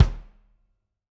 kick2.mp3